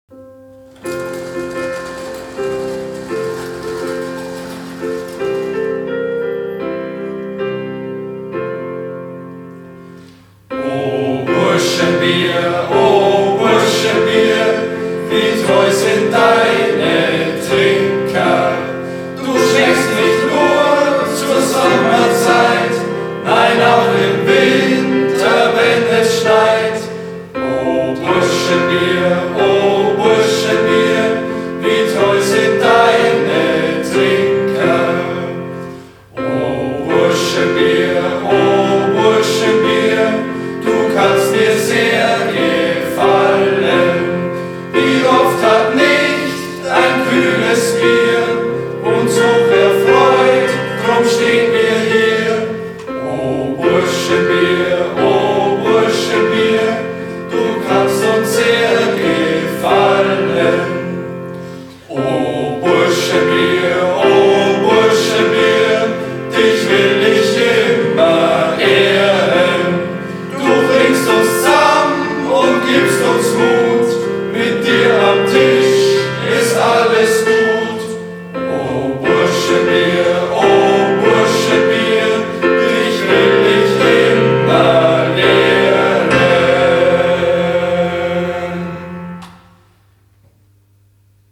Zum Fest gibt es eine Parodie des bekannten Weihnachtsliedes „O Tannenbaum“.
Nach einigen Vorbereitungen traf sich die Vorstandschaft im Haus der Pfarrgemeinde in Roding.
Mikro, Soundkarte, MacBook, Keyboard und Kamera.